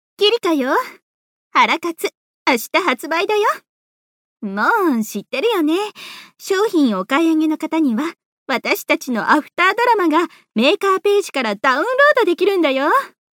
発売1日前ボイス01